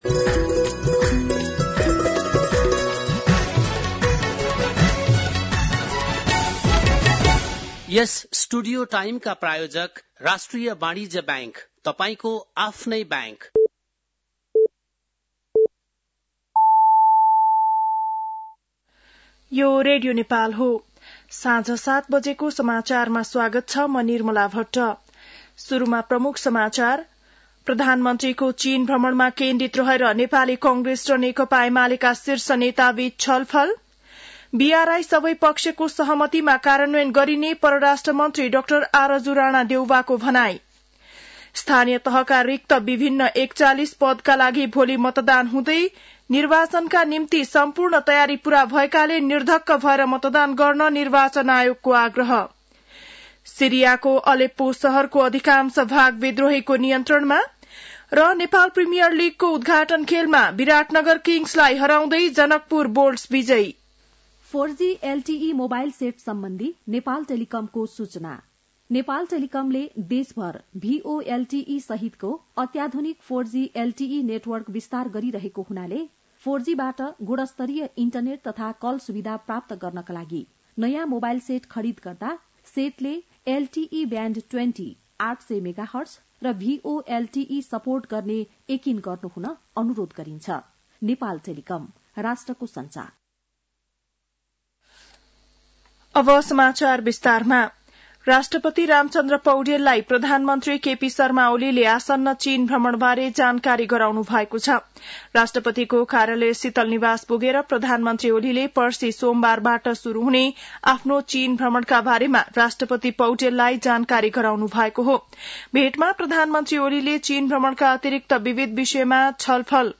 बेलुकी ७ बजेको नेपाली समाचार : १६ मंसिर , २०८१